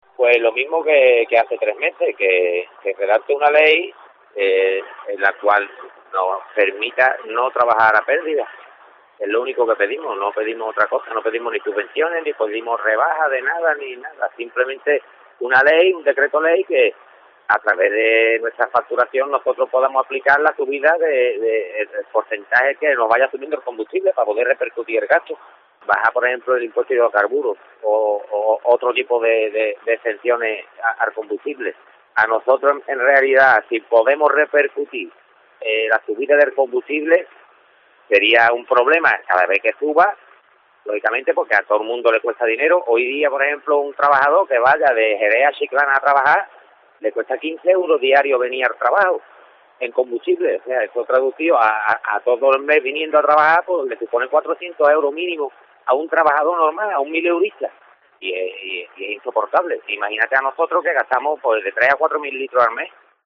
Declaraciones a COPE